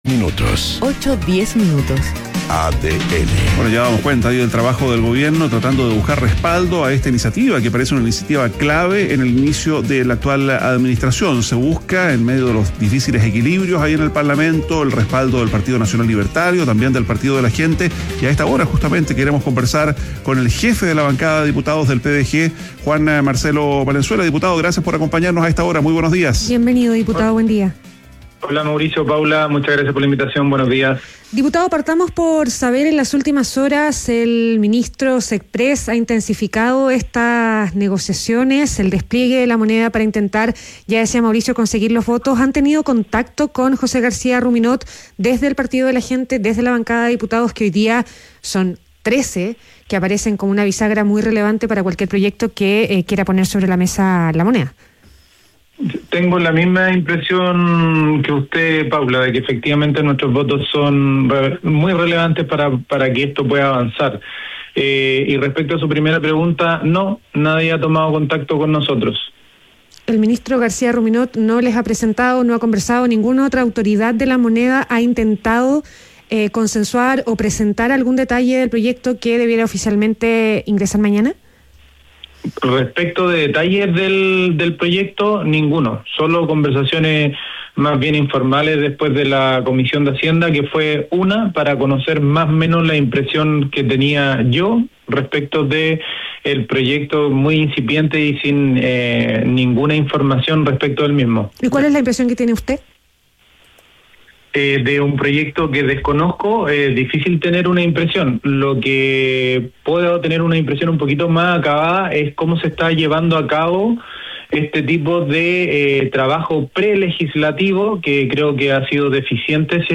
Entrevista a Juan Marcelo Valenzuela, jefe bancada diputados del Partido de la Gente - ADN Hoy